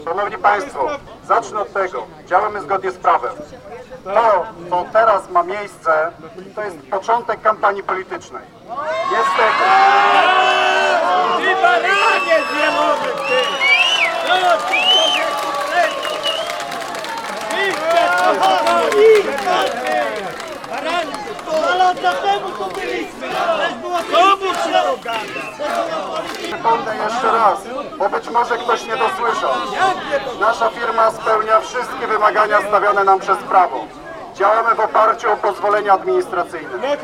protest-zary-2.mp3